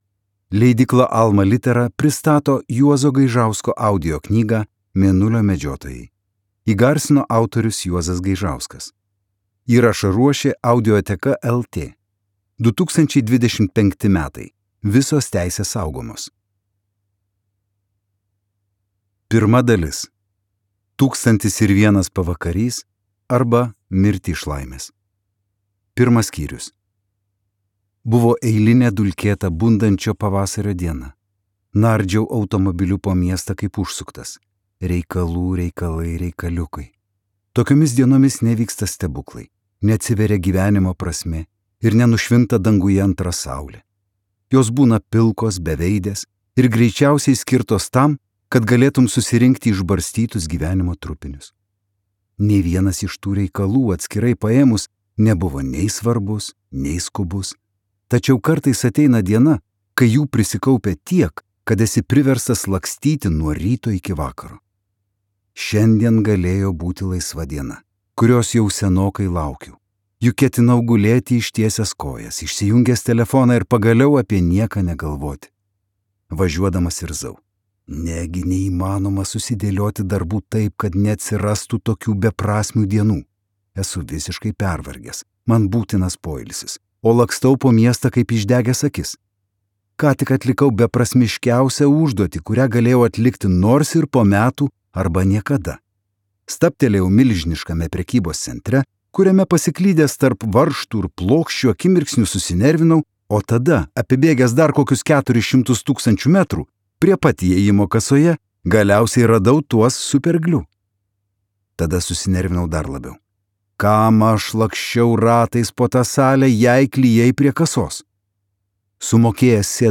Mėnulio medžiotojai | Audioknygos | baltos lankos